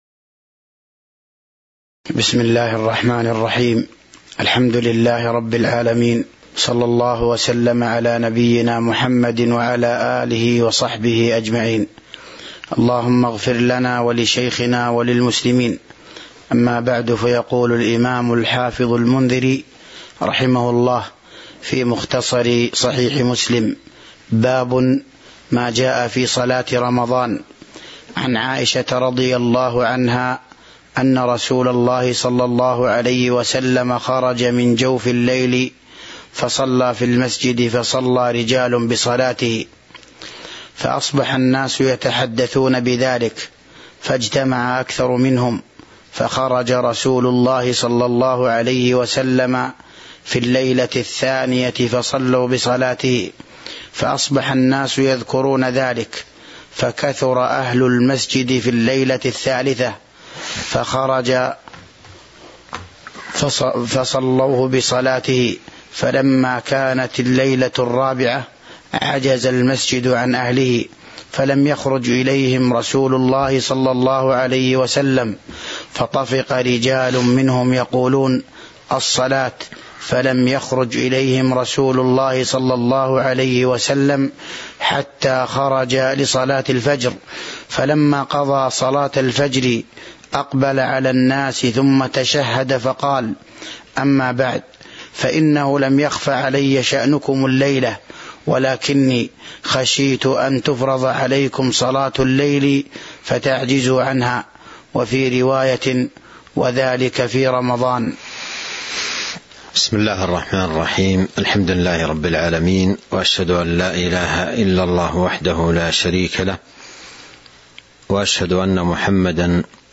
تاريخ النشر ١٣ رمضان ١٤٤٢ هـ المكان: المسجد النبوي الشيخ